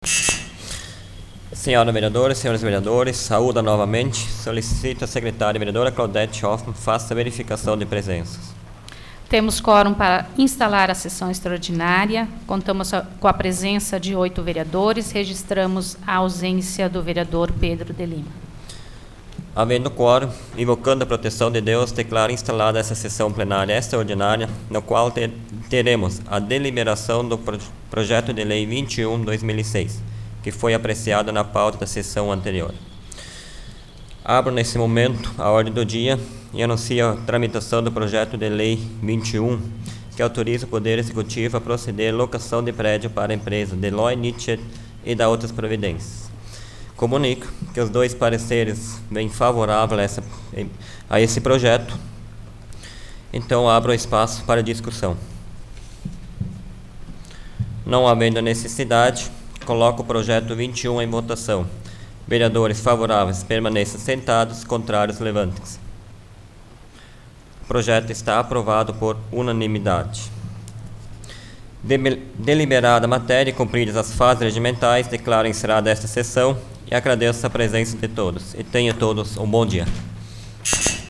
Áudio da 25ª Sessão Plenária Extraordinária da 12ª Legislatura, de 24 de julho de 2006